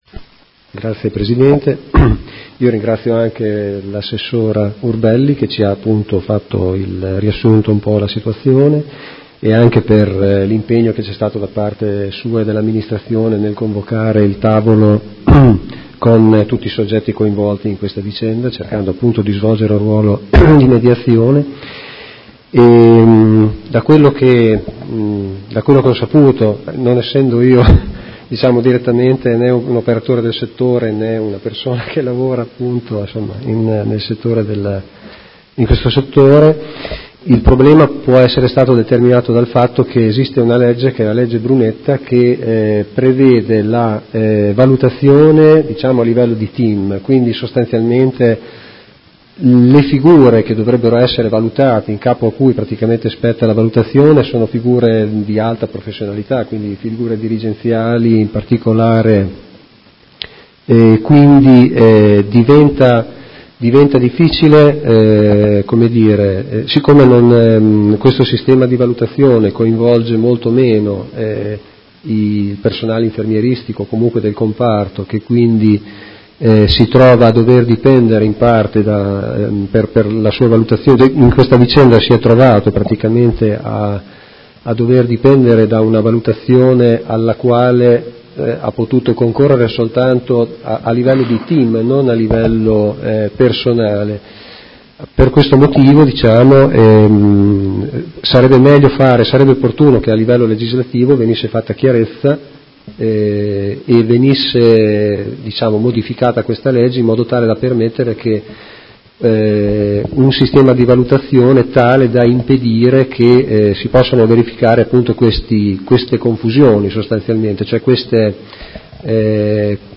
Marco Malferrari — Sito Audio Consiglio Comunale